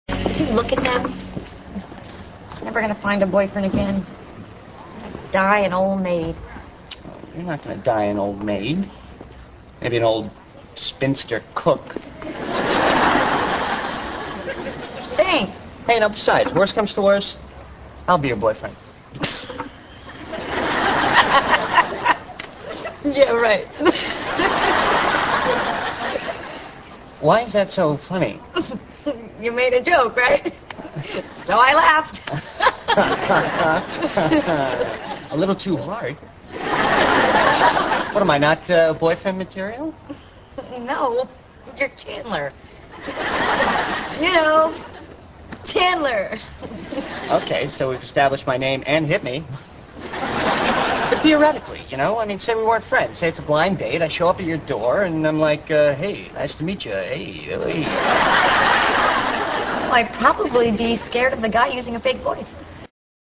听完Chandler的这个建议之后，Monica大笑着说yeah right, 这让Chandler很是不解---我可是一片好意，有什么好笑的嘛 (Why is that so funny?) Monica以为Chandler是在开玩笑，所以她笑了(You made a joke right? So I laughed.)。Chandler干笑了几声，他好像是在说真的，可就算是玩笑吧，Monica也笑得太过份了(A little to hard.), 难道自己就不是当男朋友的料(What am I not ah, boyfriend material?)